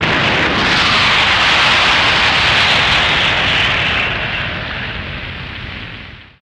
Afterburner Jetwash High Velocity